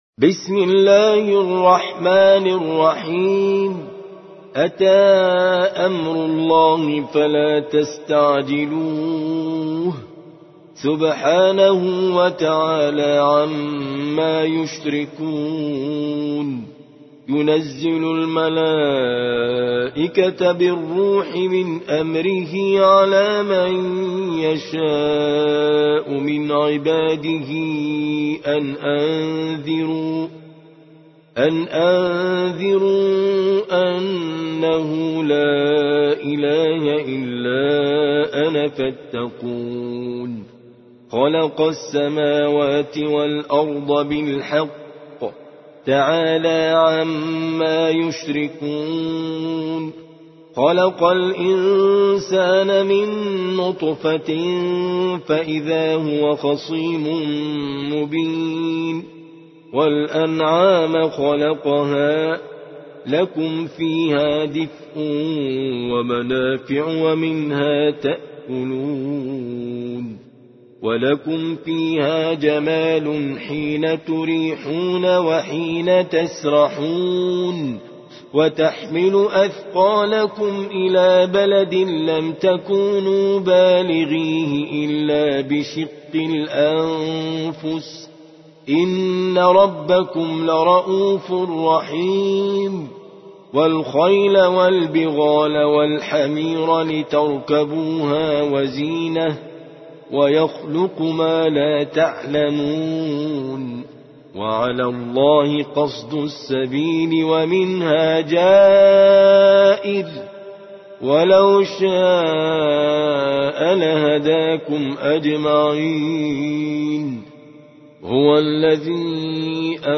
16. سورة النحل / القارئ